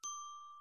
3. Xylophone, (sounds)